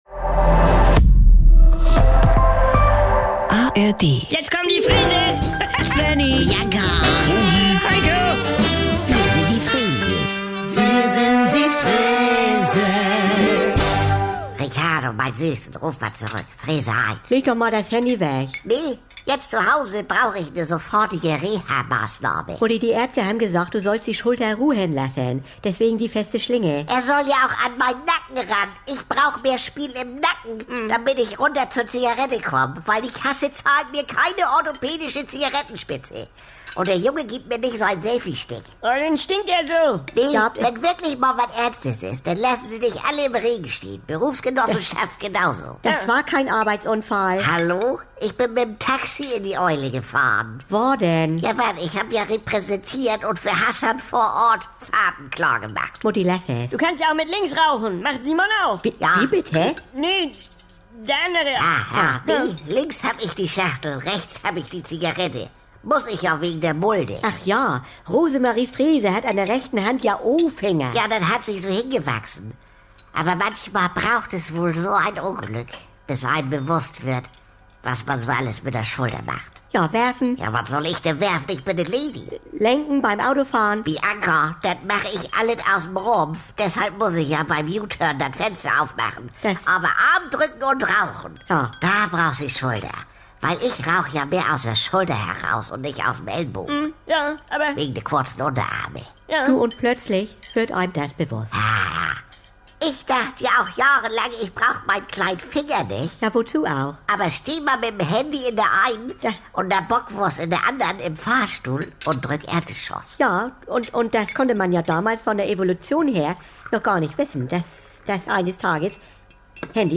Saubere Komödien NDR 2 Komödie Unterhaltung